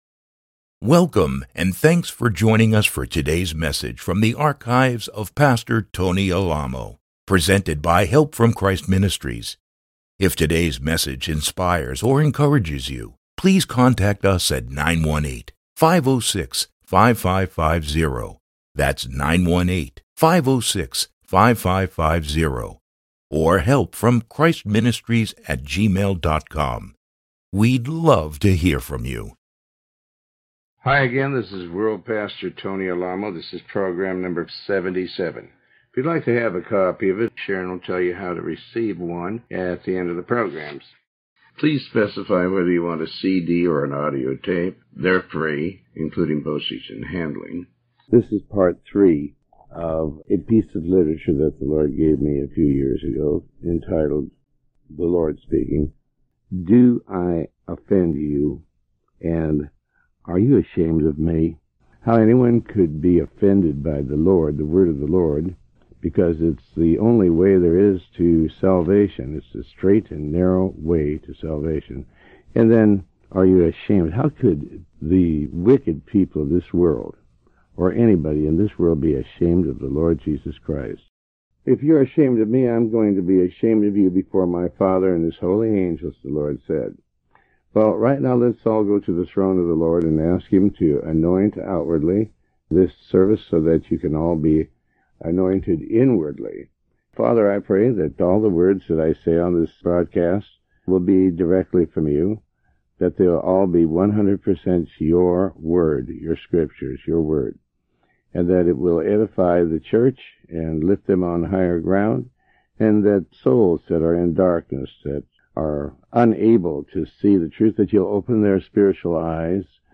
Sermon 77B